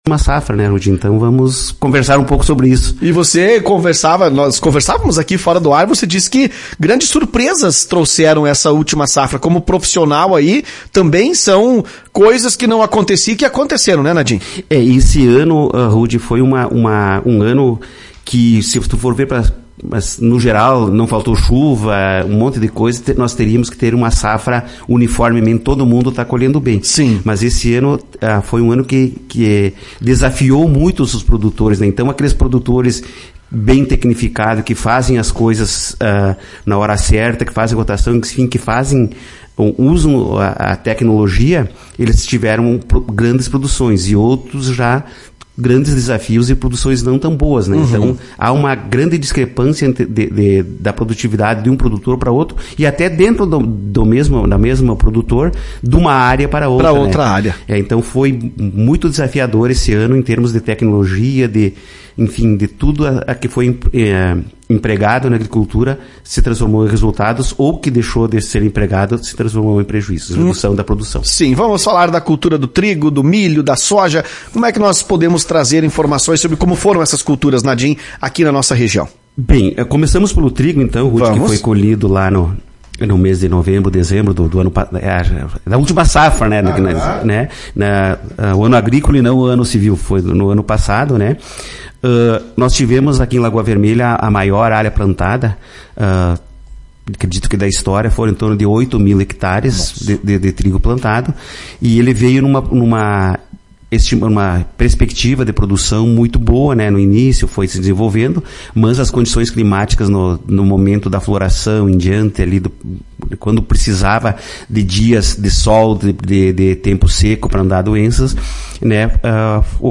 Em entrevista concedida à Tua Rádio Cacique